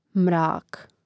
mrȃk mrak